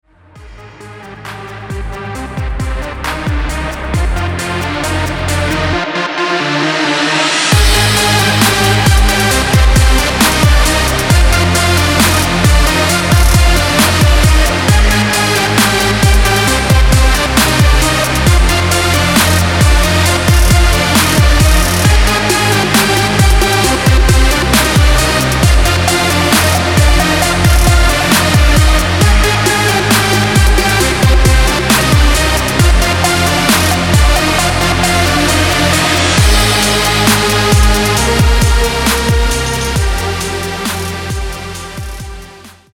• Качество: 320, Stereo
атмосферные
Electronic
без слов
witch house
Wave